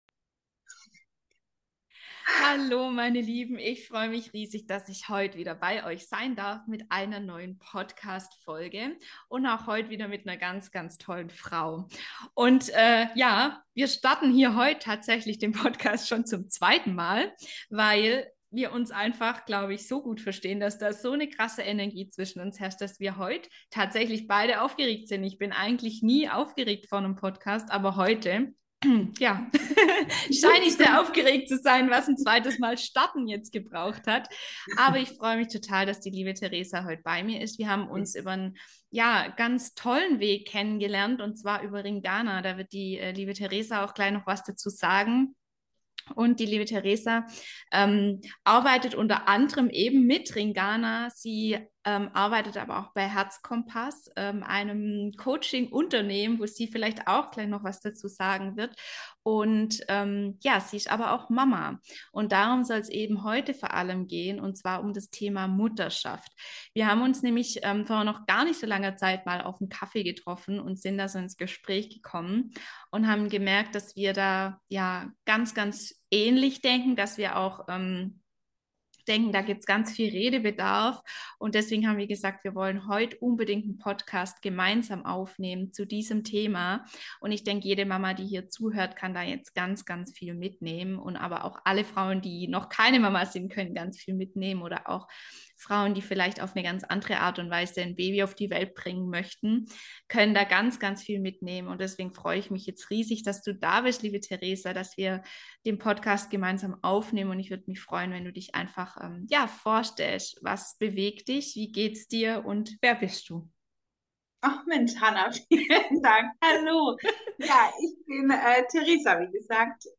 Frauengespräche mit Tiefe und Herz Podcast